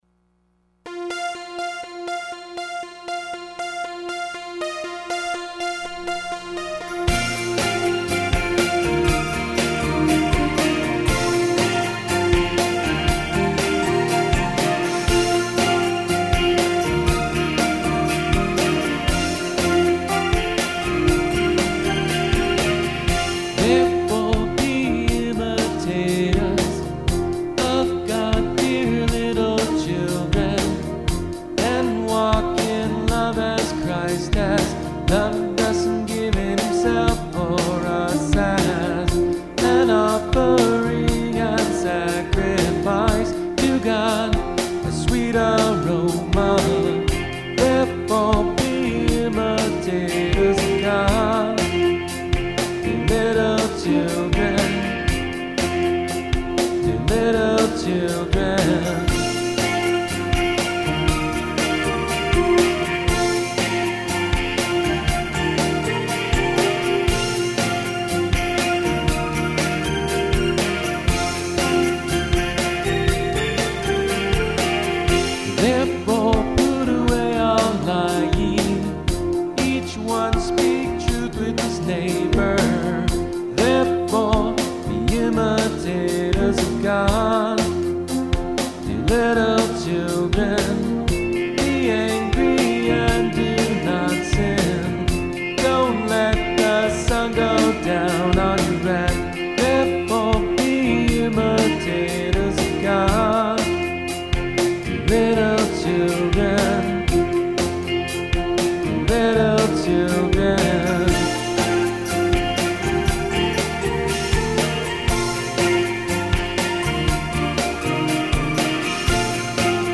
Gentle pleasing well made adult contemporary